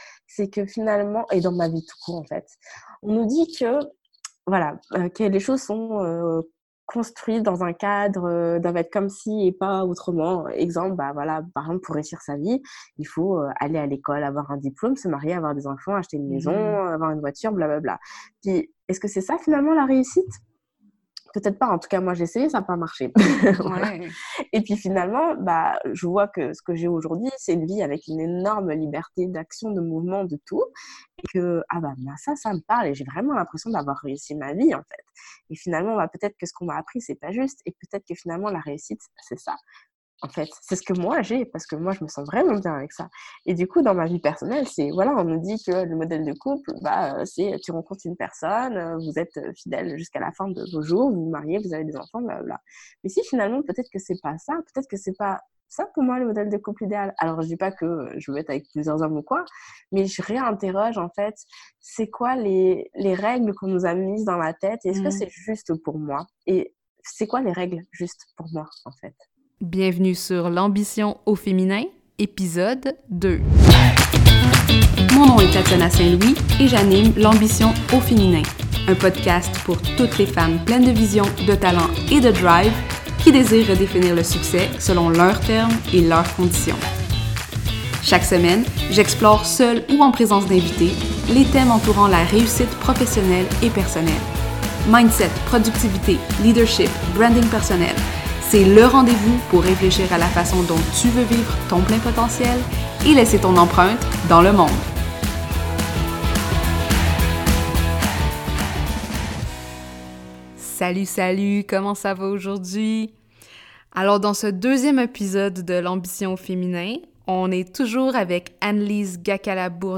la deuxième partie de son entrevue.